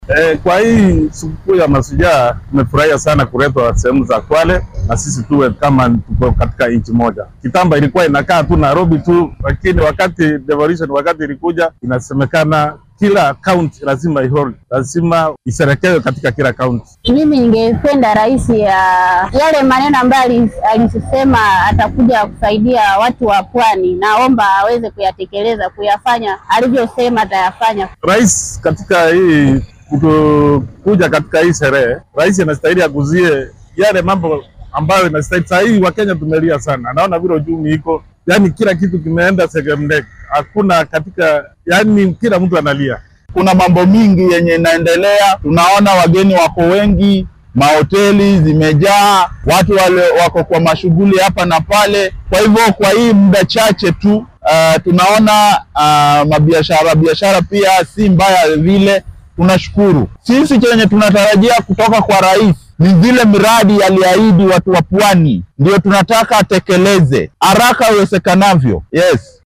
Qaar ka mid ah shacabka ku nool ismaamulka Kwale oo markii ugu horreysay lagu qabanaya dabbaaldeg qaran ayaa madaxweyne William Ruto ka codsaday inuu oofiyo waxyaabihii uu u ballan qaaday shacabka gobolka Xeebta.